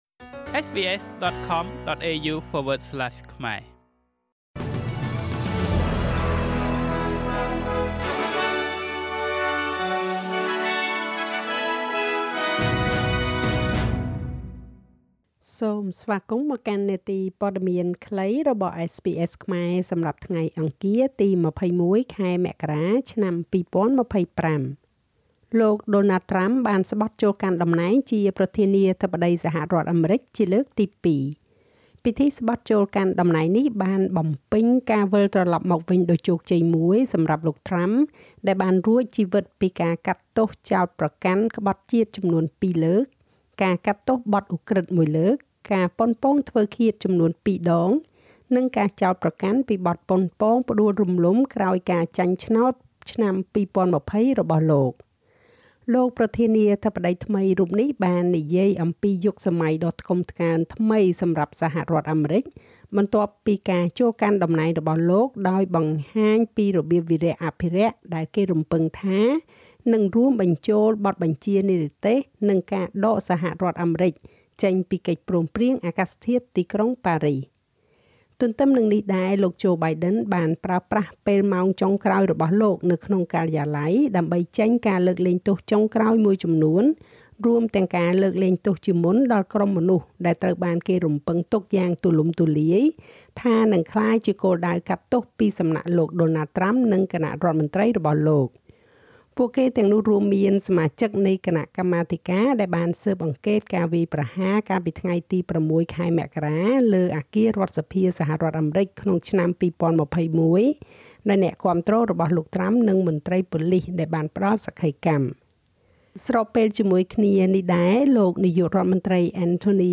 នាទីព័ត៌មានខ្លីរបស់SBSខ្មែរ សម្រាប់ថ្ងៃអង្គារ ទី២១ ខែមករា ឆ្នាំ២០២៥